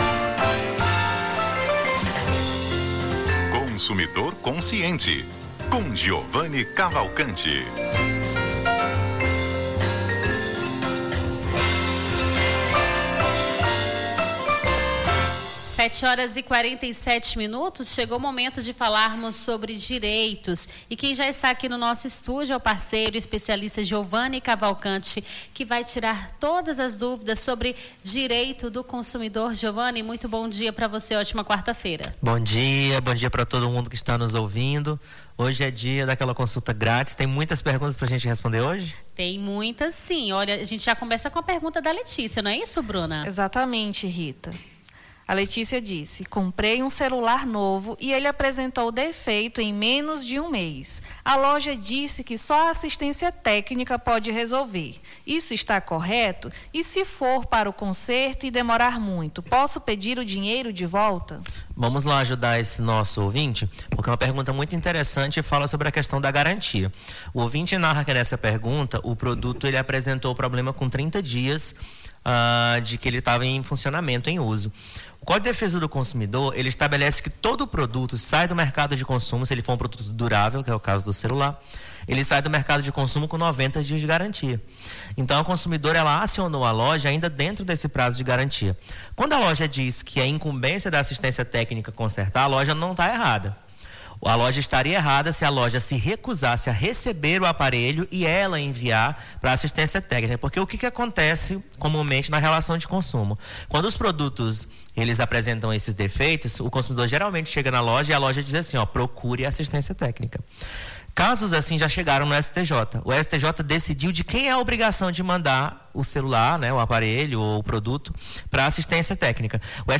Consumidor Consciente: advogado esclarece dúvidas sobre direito do consumidor